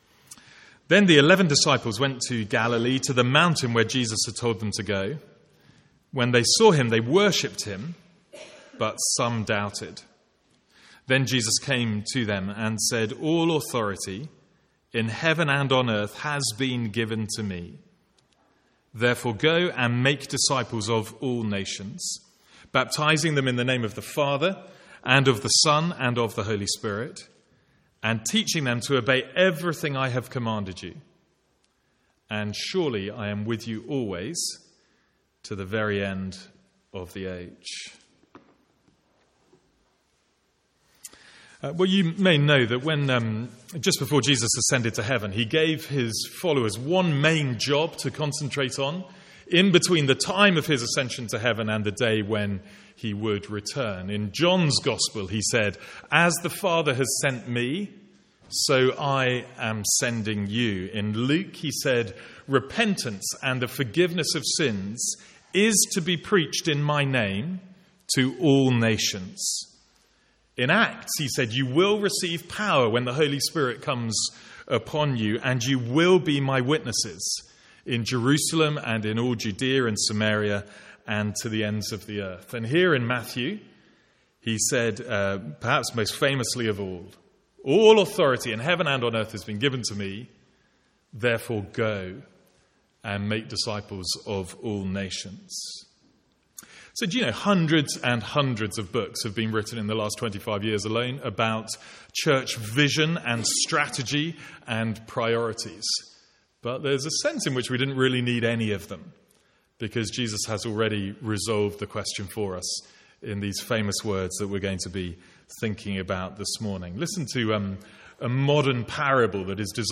From the Sunday morning series in Matthew.